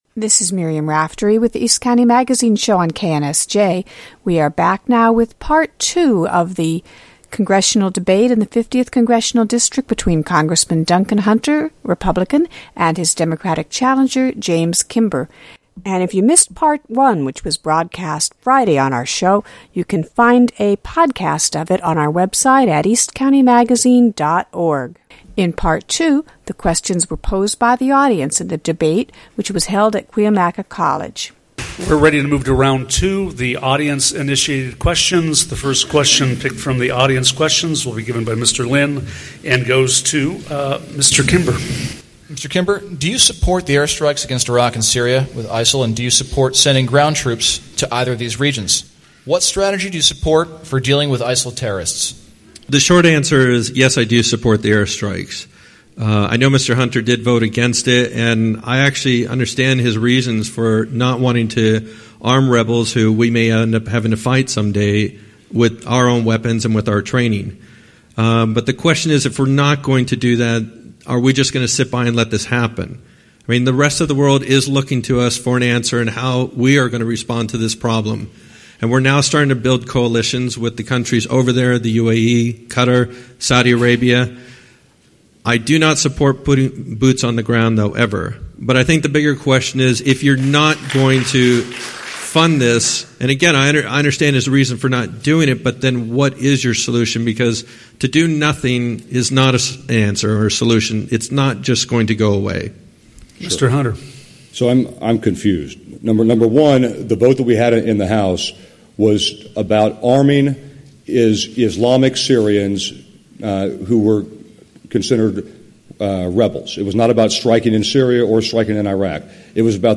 Cuyamaca College
Duncan Hunter debate